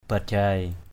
/ba-ʤaɪ/ (cv.) pajai p=j (d.) xứ Phú Hài (gần Phan Thiết) = pays de Phú-hài (près de Phan-thiet). Phú-Hài land (not far from Phanthiet city).